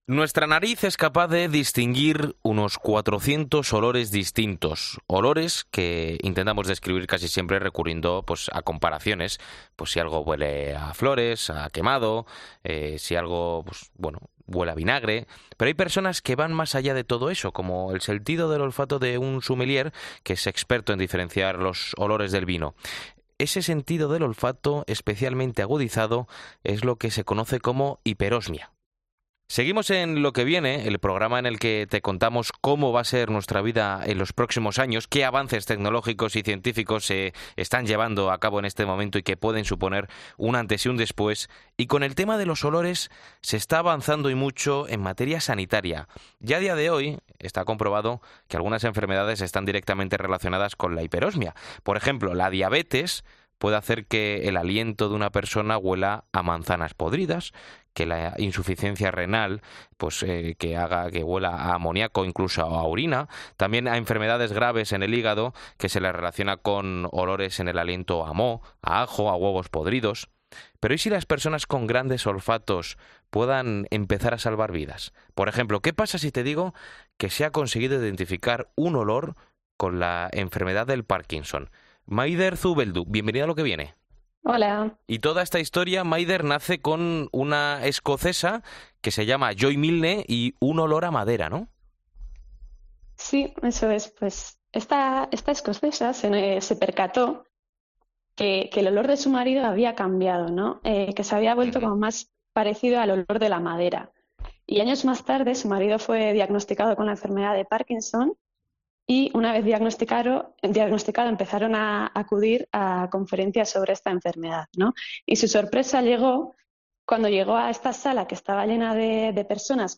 En 'Lo Que Viene' analizamos con una experta cómo podemos, con solo oler a una persona, saber si está enferma o no y ayudarle a prevenirlo